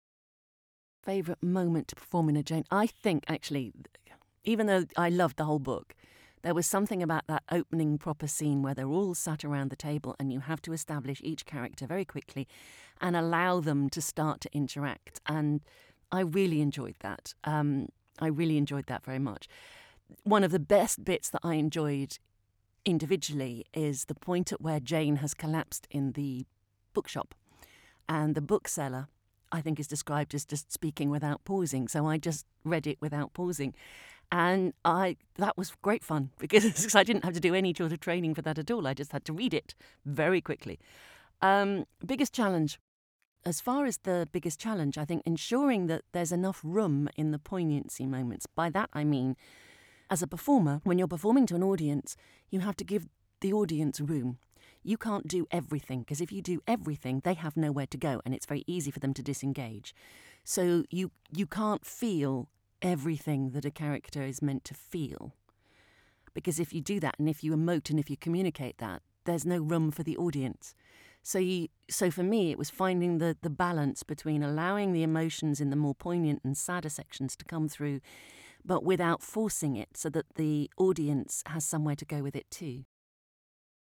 I hope you dug listening to the interview as much as I did and will take a listen to the audiobook.